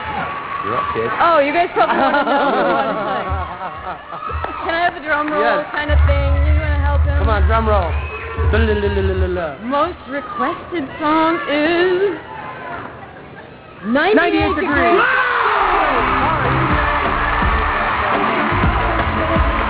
Rachael announcing the winner of a most requested song (that's all I know)
radiomusic.wav